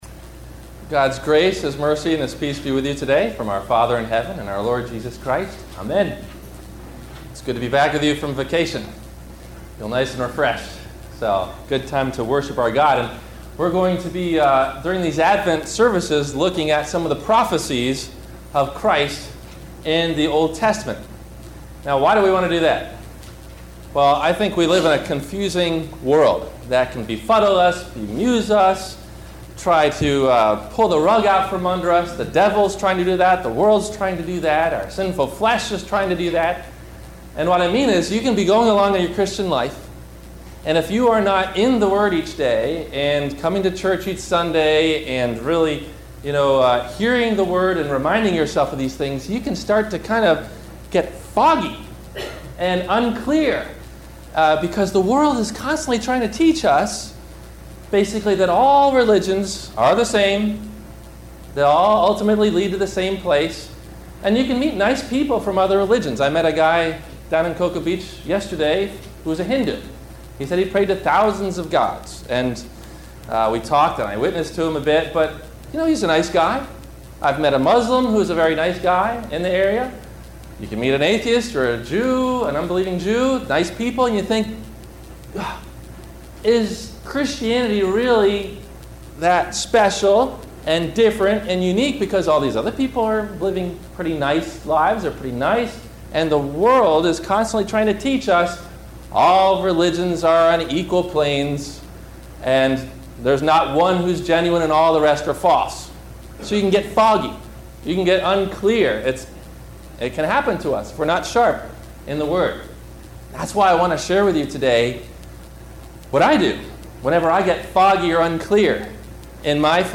Listen for these questions and answers about Can God Use Even Little Me?, below in the 1-part Video or 1-part MP3 Audio Sermon below.